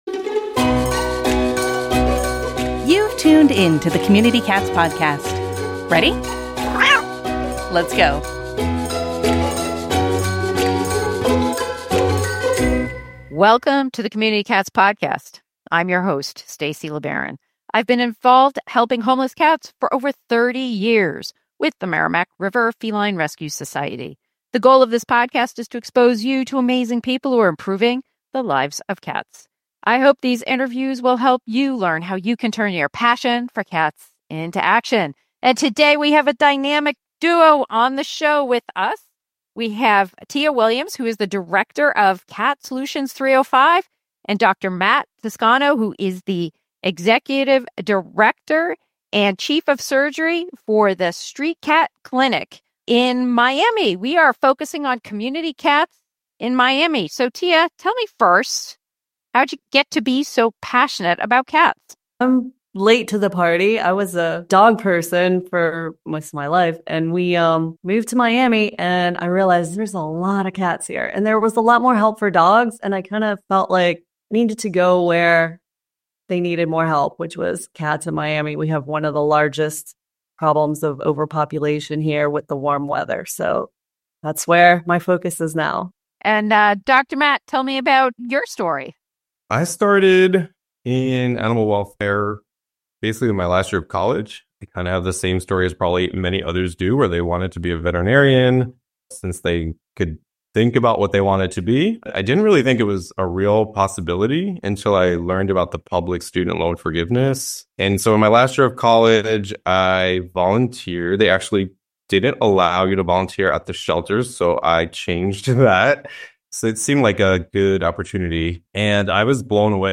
The conversation dives into the real-life logistics of operating a high-volume clinic and the vital role of empathy, flexibility, and data-driven strategies.